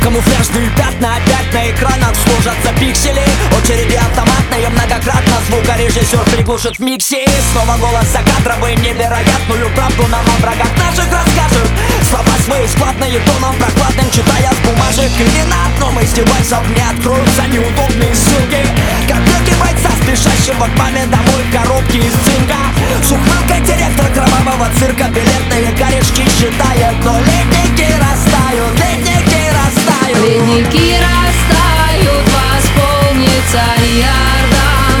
Жанр: Хип-Хоп / Рэп / Рок / Русский рэп / Русские